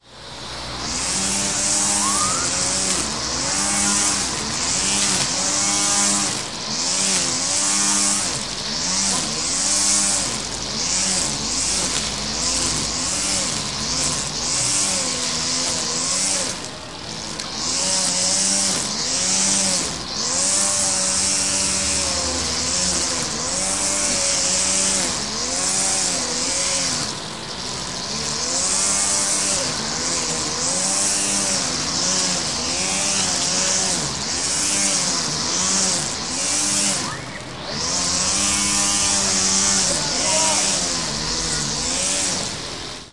描述：割草机发出的声音，背景噪音也有。
Tag: 氛围 工业 机械